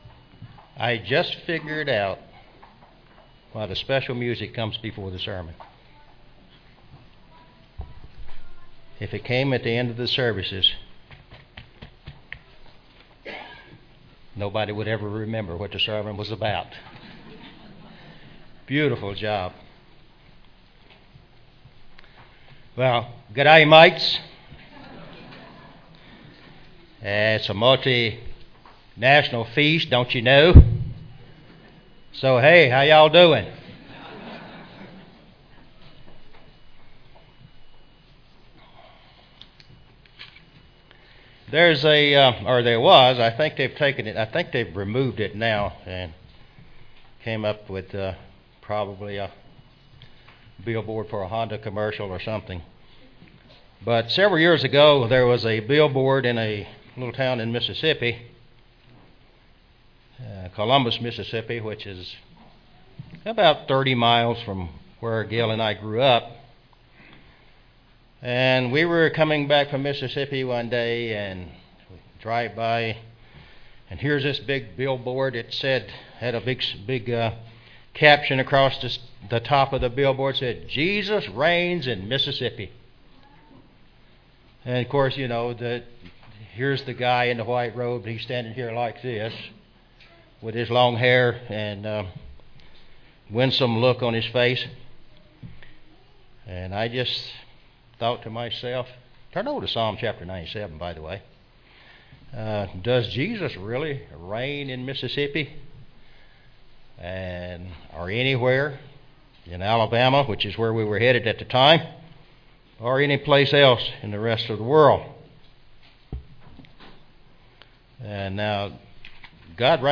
This sermon was given at the Canmore, Alberta 2014 Feast site.